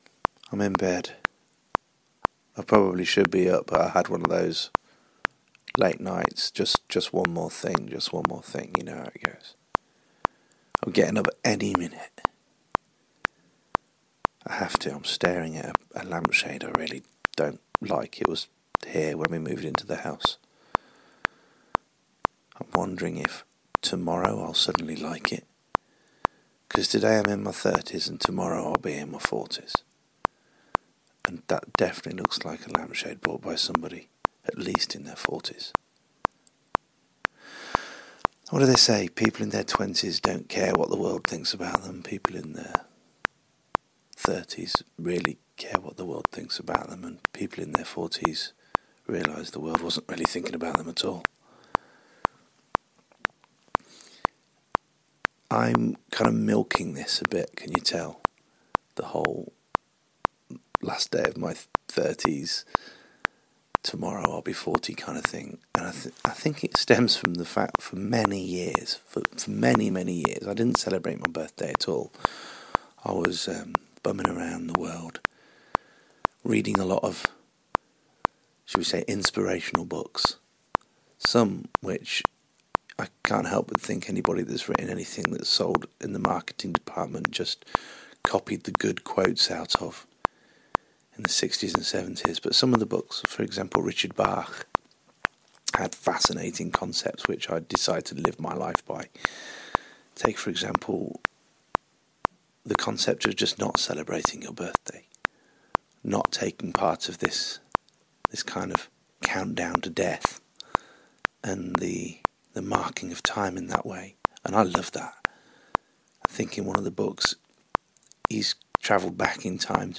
An audio blog contemplating the passing of time, from one of audioboo's most prolific users.